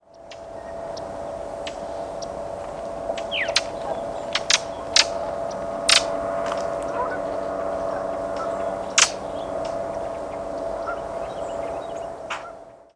Brown Thrasher Toxostoma rufum
Frequently gives "tchk" calls or rapid "tchk" series in short diurnal flights.
Diurnal calling sequences: